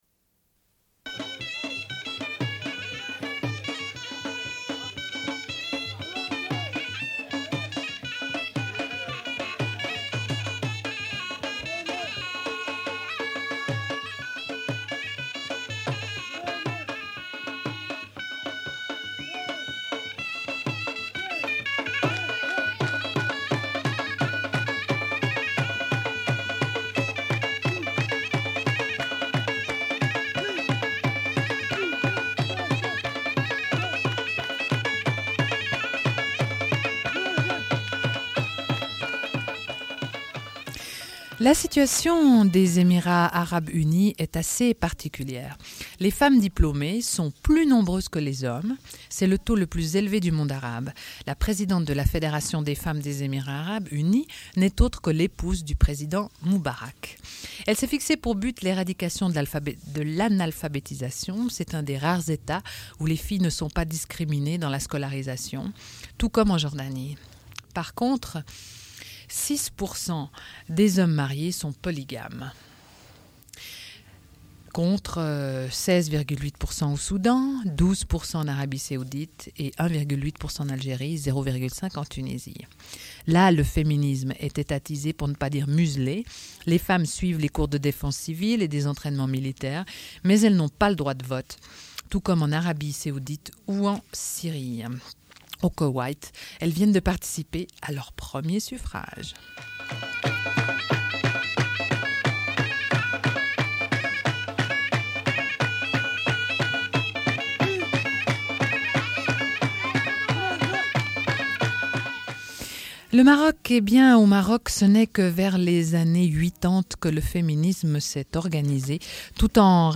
Une cassette audio, face A31:23